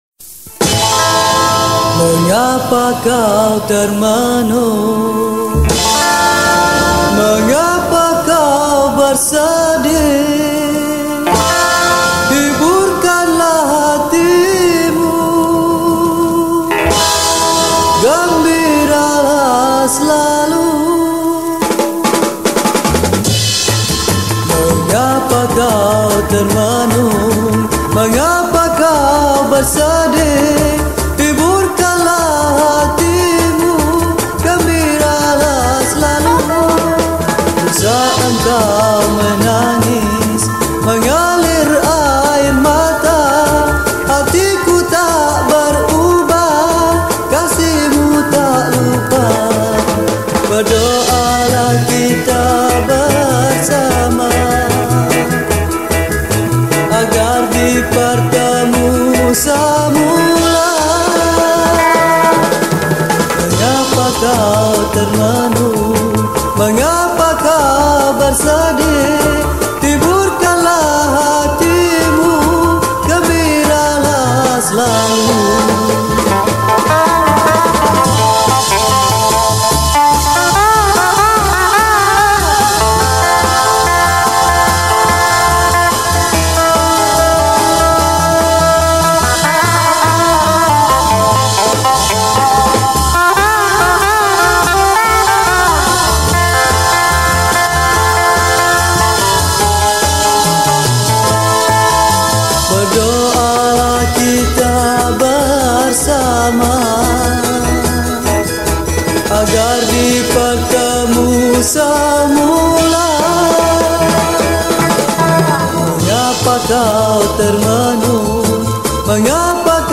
Malay Songs , Pop Yeh Yeh